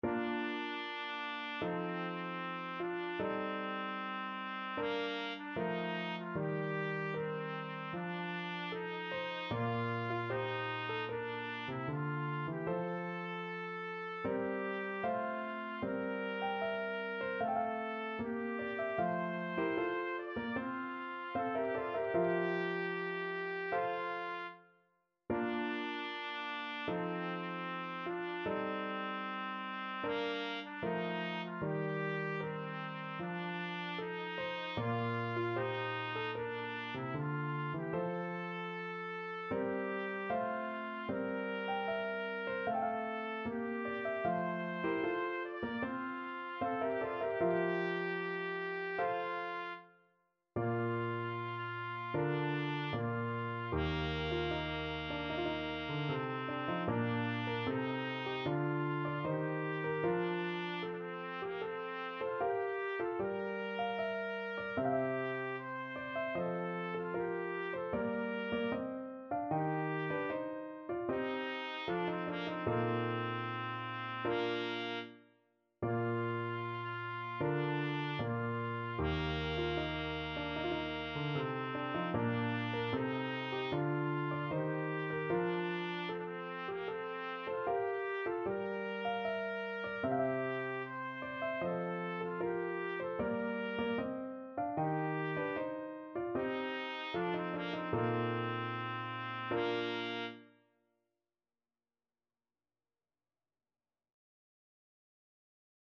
Trumpet Classical Trumpet Classical Trumpet Free Sheet Music March from Idomeneo, Act 3
Bb major (Sounding Pitch) C major (Trumpet in Bb) (View more Bb major Music for Trumpet )
4/4 (View more 4/4 Music)
Andante =76
Trumpet  (View more Easy Trumpet Music)
Classical (View more Classical Trumpet Music)